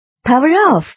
power_off.mp3